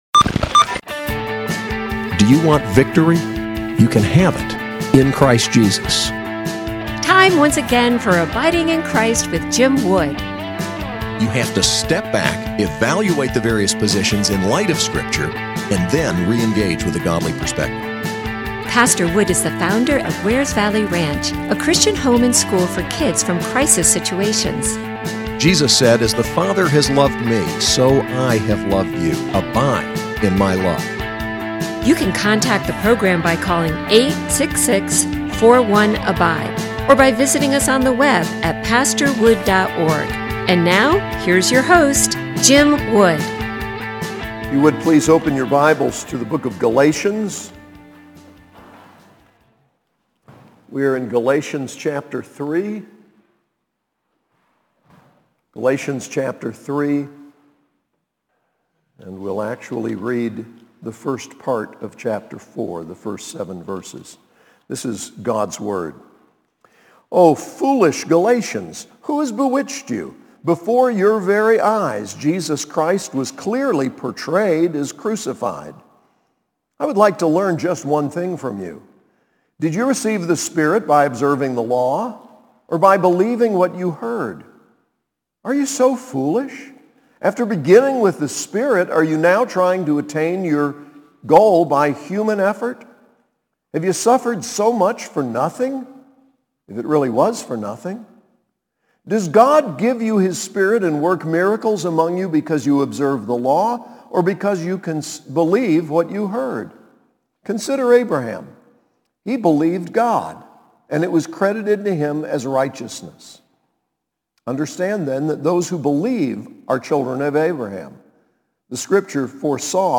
SAS Chapel: Galatians 3:1-4:7